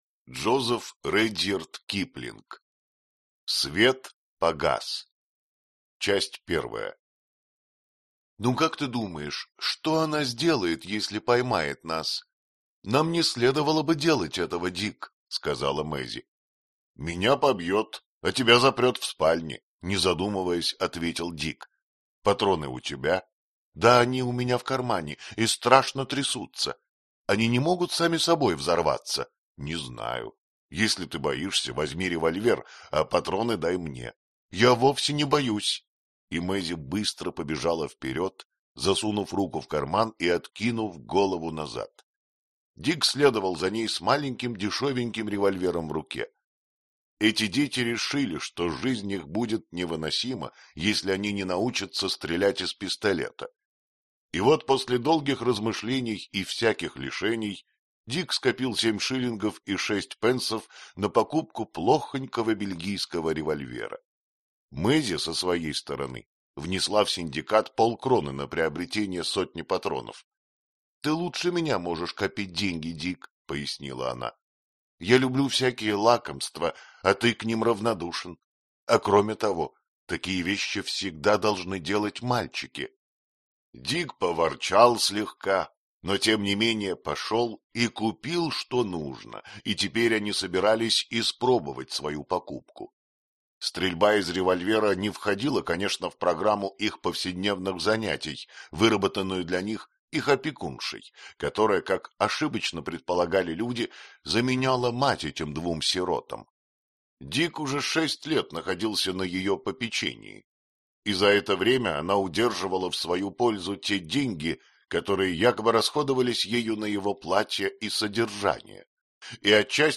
Аудиокнига Свет погас | Библиотека аудиокниг